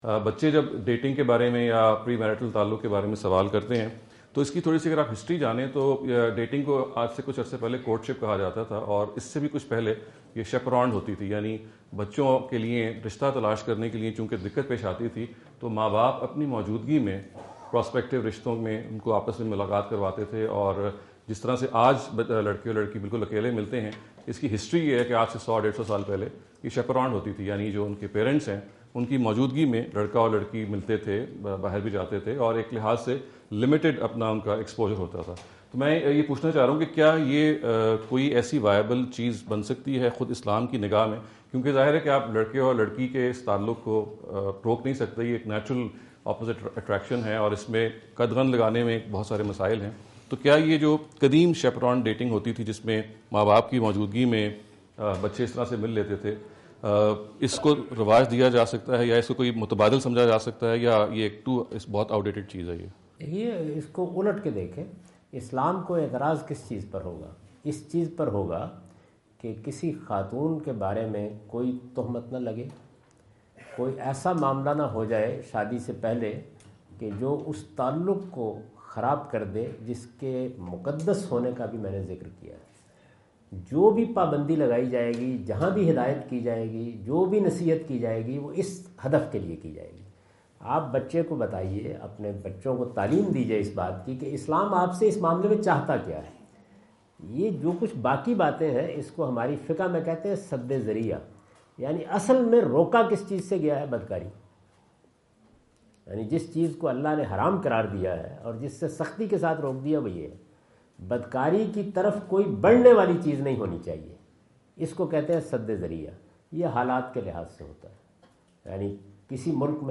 Javed Ahmad Ghamidi answer the question about "Premarital Meetings" asked at Corona (Los Angeles) on October 22,2017.
جاوید احمد غامدی اپنے دورہ امریکہ 2017 کے دوران کورونا (لاس اینجلس) میں "لڑکی اور لڑکے کا شادی سے پہلے ملنا" سے متعلق ایک سوال کا جواب دے رہے ہیں۔